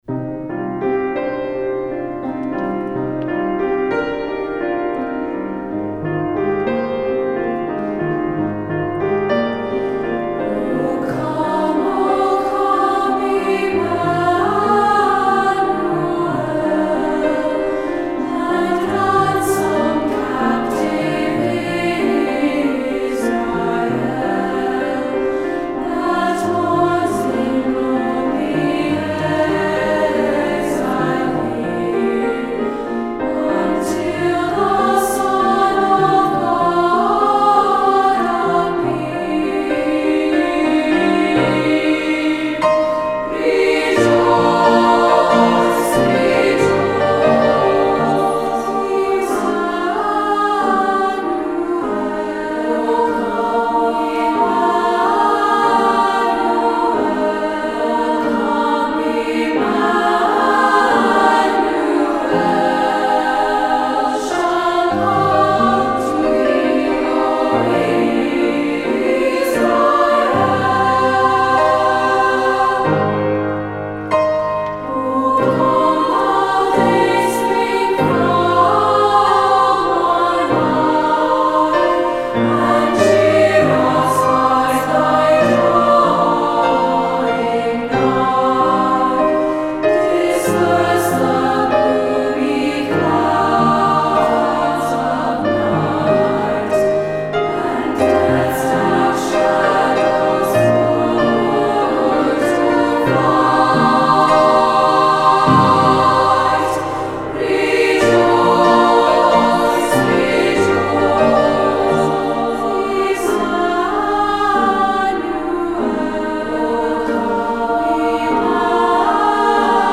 Voicing: 2 or 3-Part and Piano